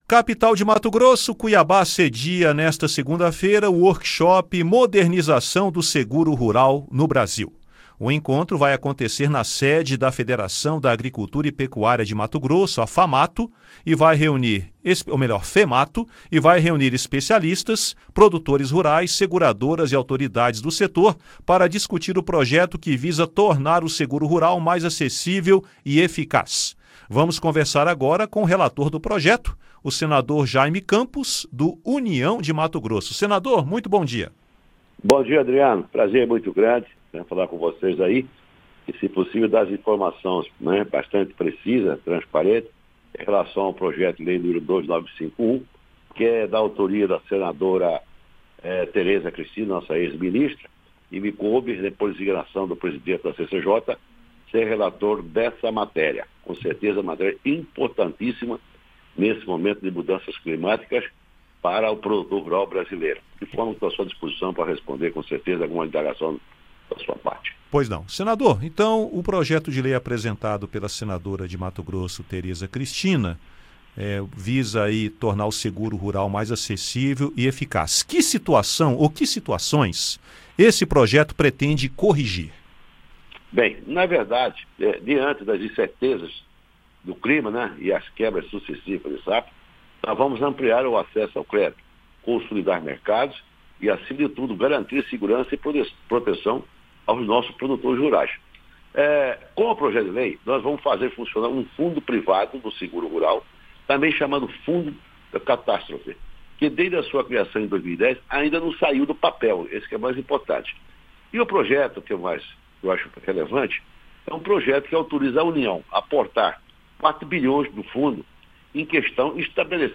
O relator do projeto, senador Jayme Campos (União-MT), explica os principais pontos da matéria, entre eles a proposta para baratear o seguro rural.